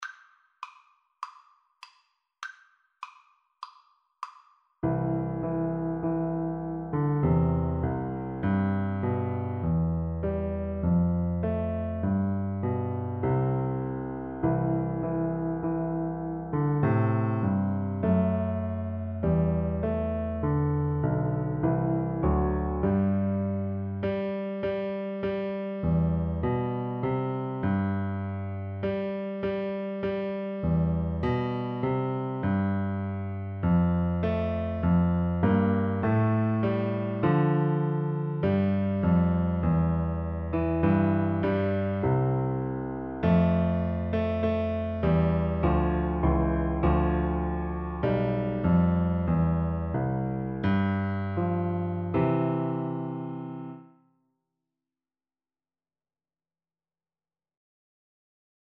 4/4 (View more 4/4 Music)
Piano Duet  (View more Beginners Piano Duet Music)
Classical (View more Classical Piano Duet Music)